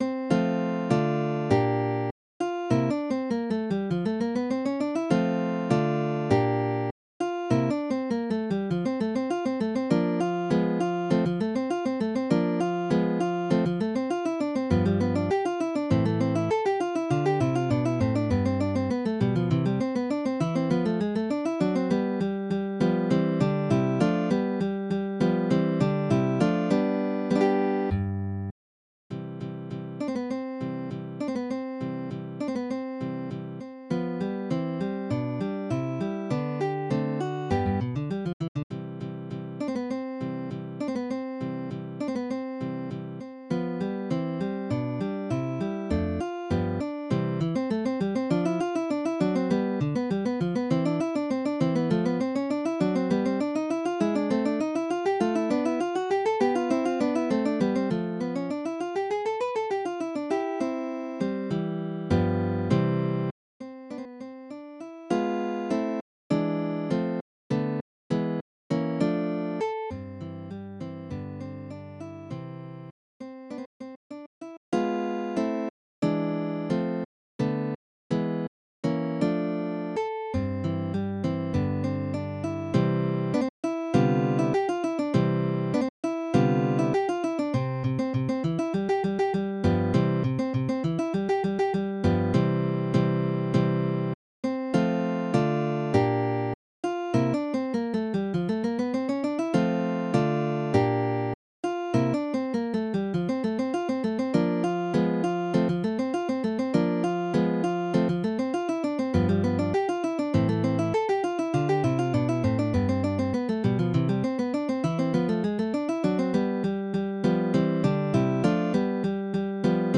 Midi音楽が聴けます 4 340円